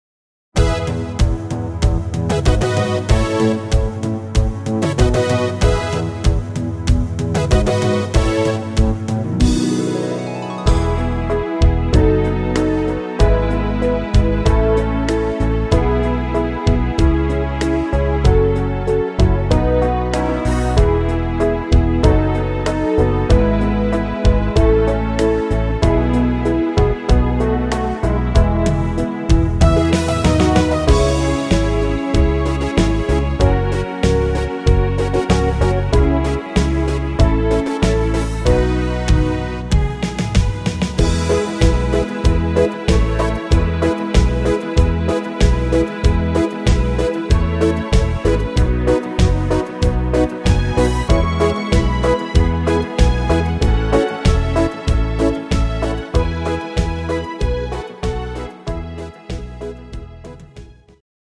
Rhythmus  Slow Beat
Art  Schlager 90er, Deutsch, Weibliche Interpreten